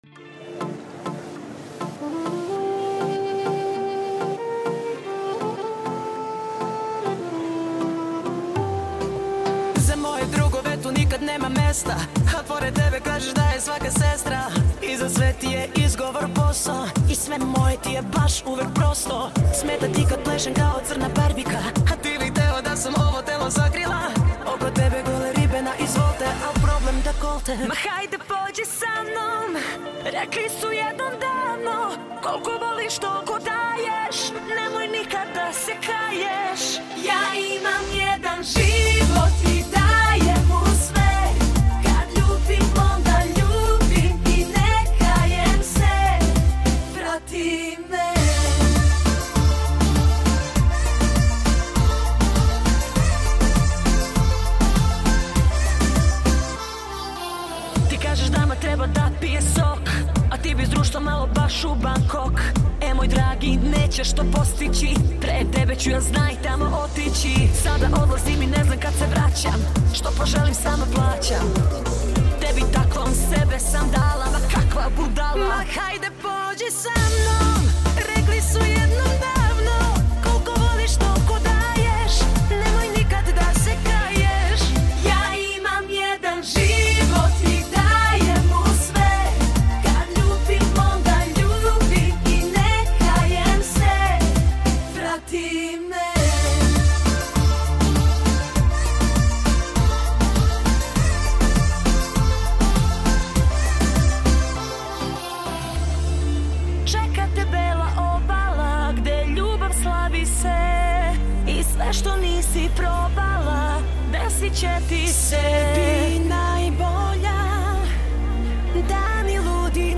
Duet women song